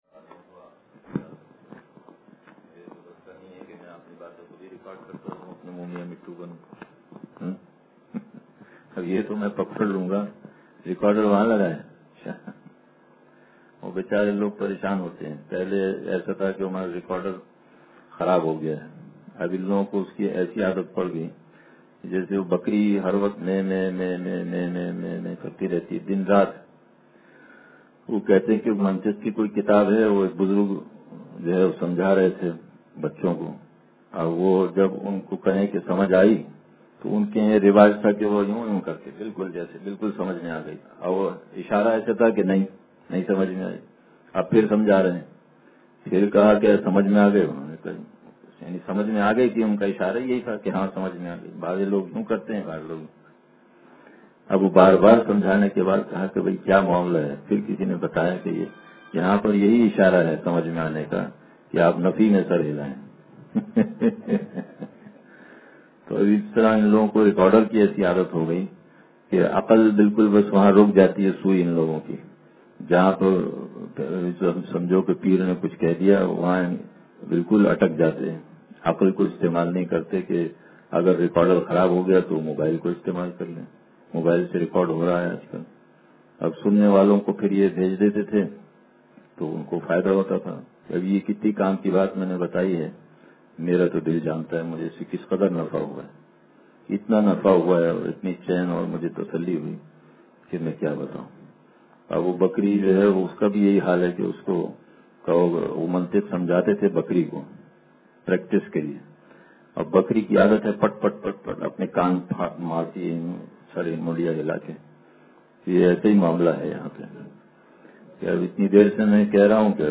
نہیں اٹھتی ہے ترے سنگِ در سے اب جبیں ساقی – اتوار بیان